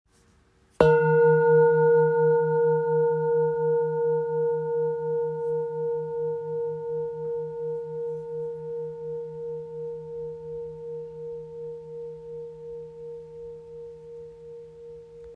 Authentic Ancient Singing Bowl – 28cm
This rare, authentic antique Himalayan singing bowl carries centuries of history within its form.
When played, the bowl produces a deep, hauntingly resonant sound with rich layers of harmonics that linger in the air. Its vibrations are grounding and powerful, ideal for meditation, sound healing, or ceremonial practice.
The bowl measures 28cm in diameter.